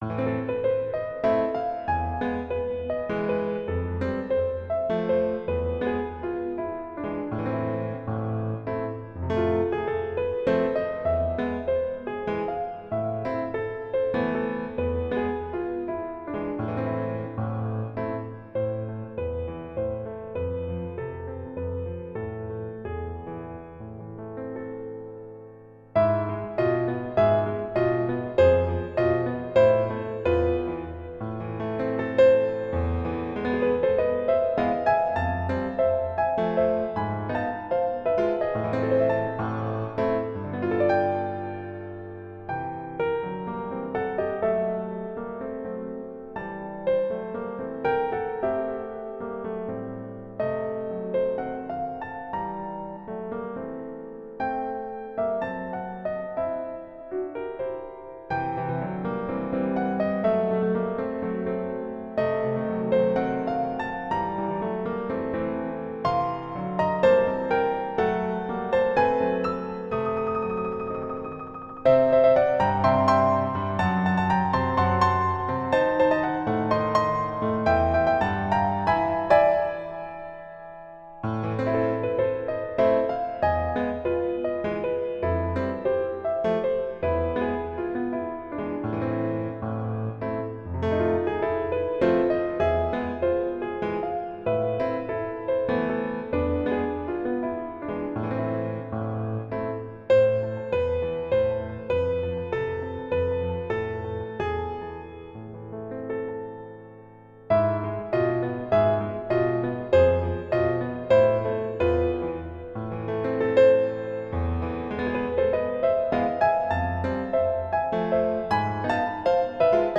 Magyar - Piano Music, Solo Keyboard
Here is another part of the Mediterranean Suite, this time using hungarian minor and hungarian major scales.